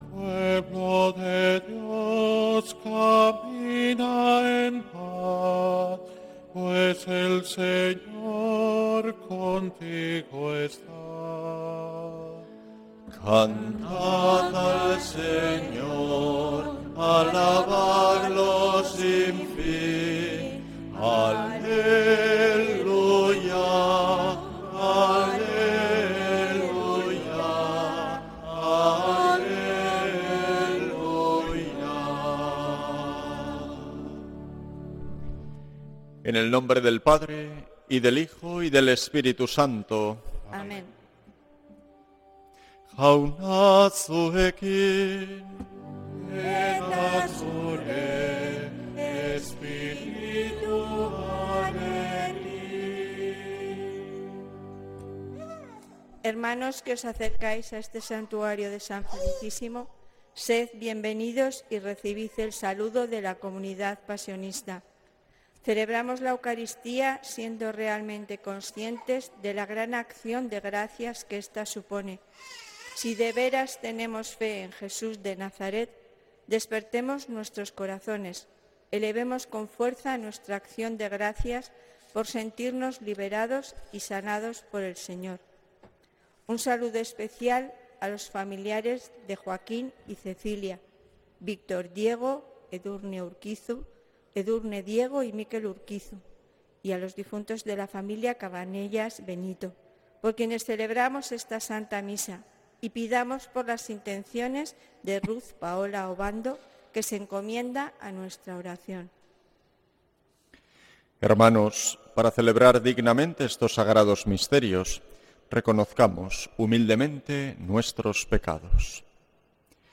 Santa Misa desde San Felicísimo en Deusto, domingo 12 de octubre de 2025